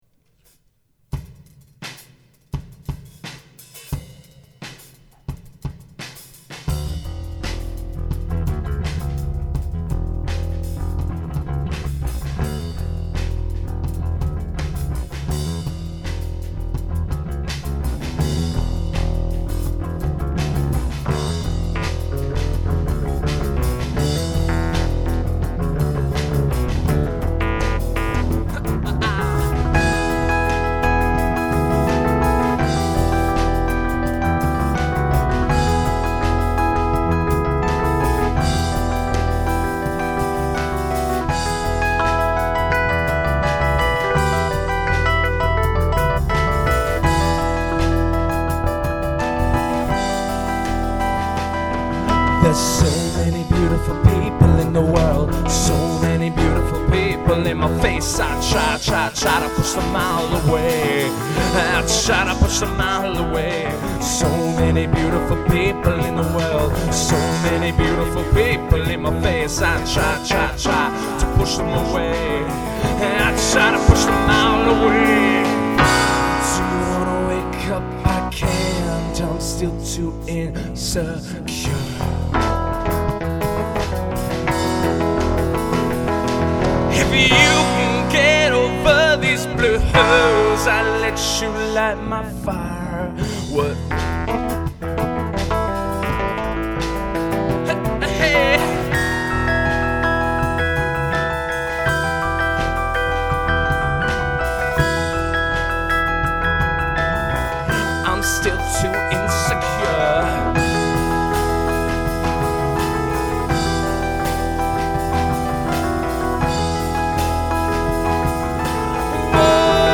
Full Band soundboard recording
Bass
Drums